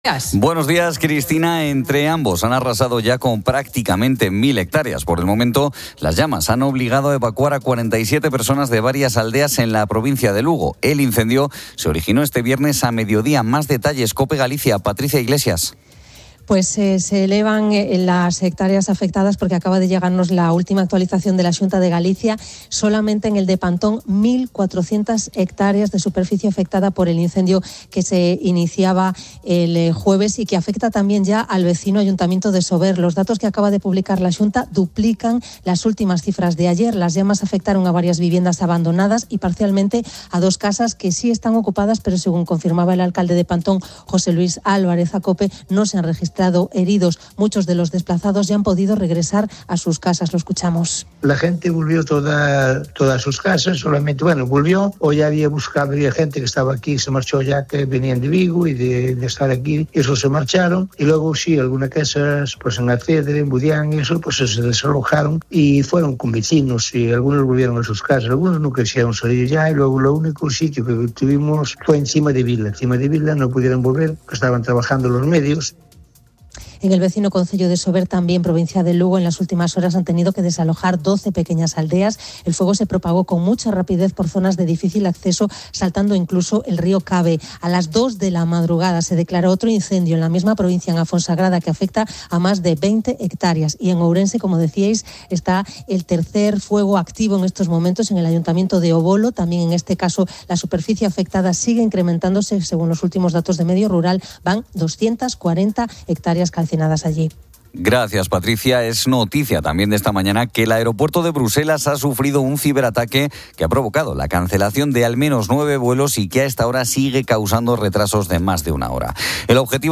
Fin de Semana 10:00H | 20 SEP 2025 | Fin de Semana Editorial de Cristina López Schlichting desde la Fundación Cruzcampo de Sevilla. Antonio Jiménez repasa con Cristina la actualidad de la semana. Marian Rojas explica cómo puede afectarnos el otoño a nivel mental.